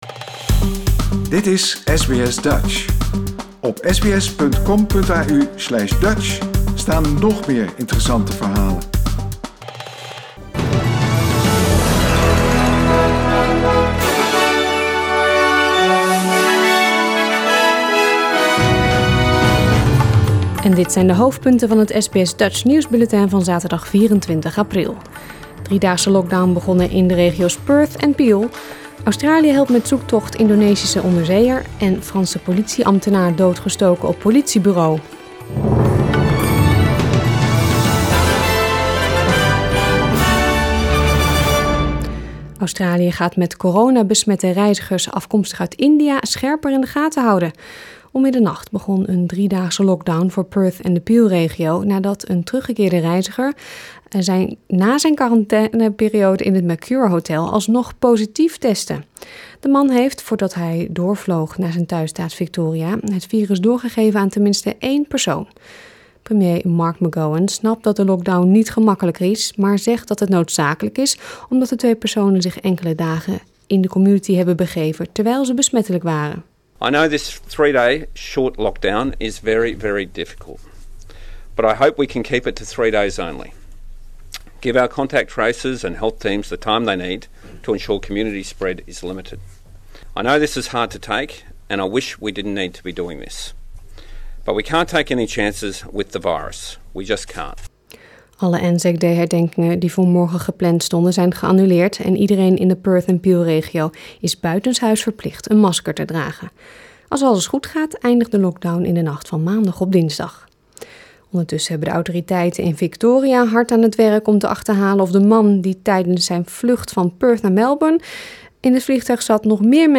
Nederlands/Australisch SBS Dutch nieuwsbulletin zaterdag 24 april 2021